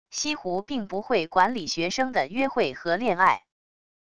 西湖并不会管理学生的约会和恋爱wav音频生成系统WAV Audio Player